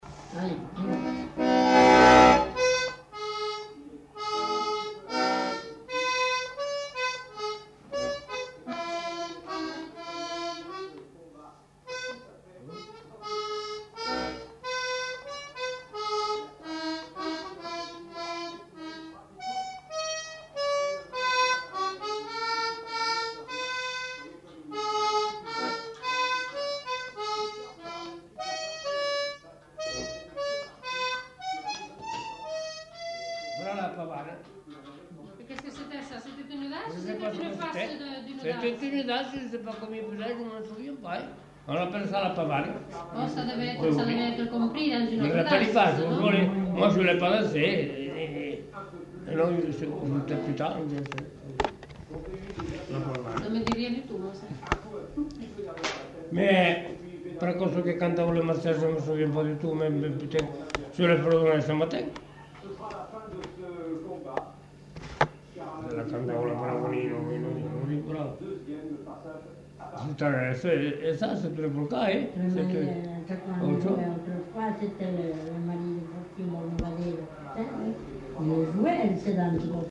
Pavane